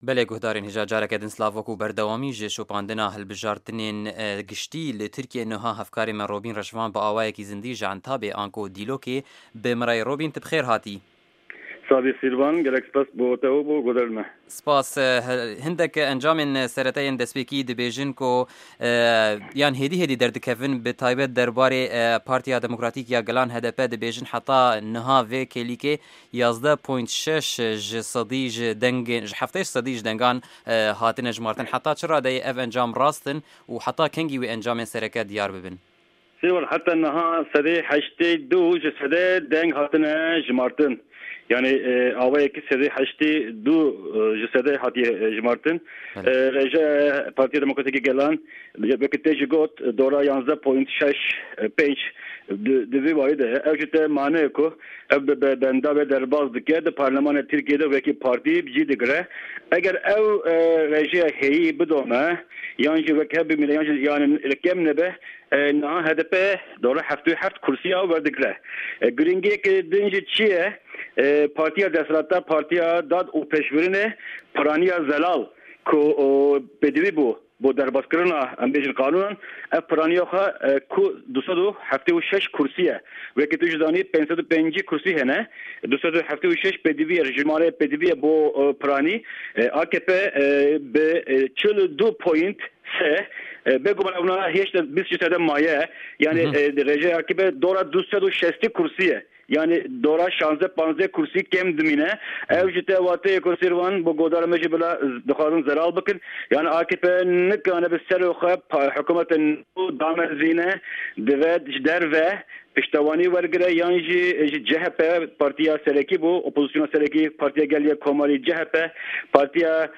Hevpeyvîn ligel Nûçegihanên Me li Tirkiyê